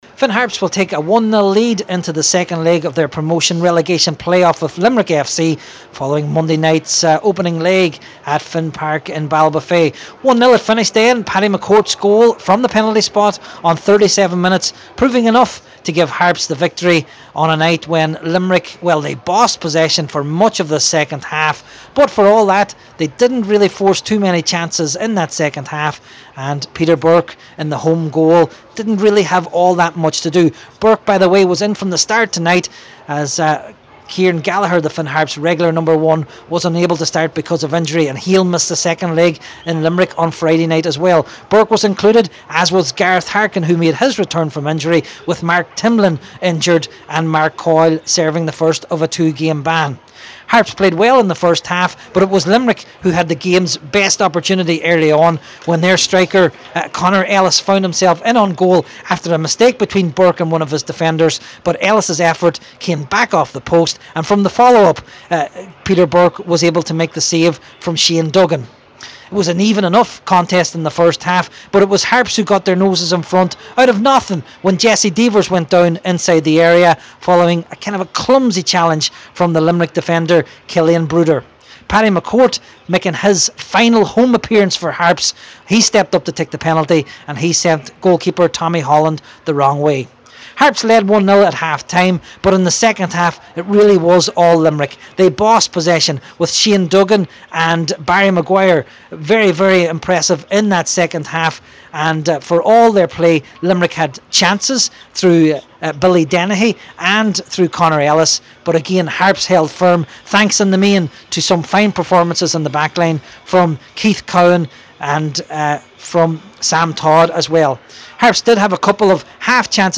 reports from Finn Park for Highland Radio Sport…